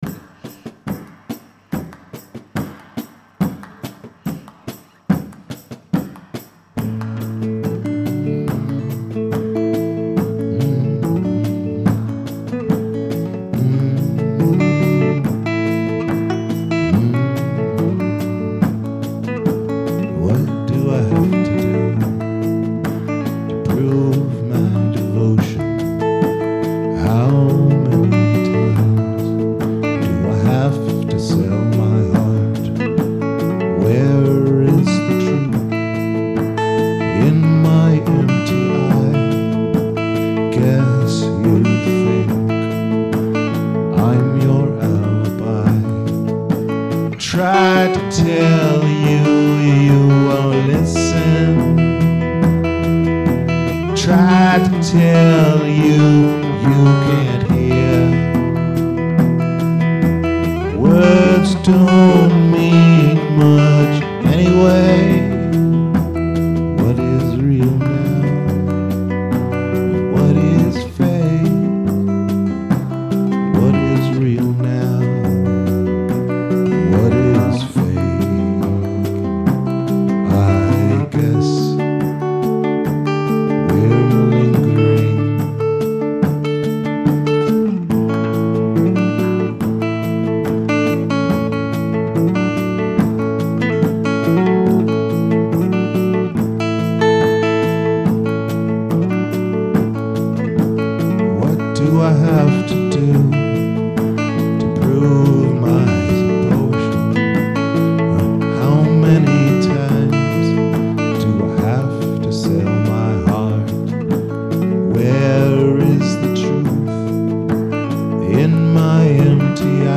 The Available
Rehearsals 18.2.2012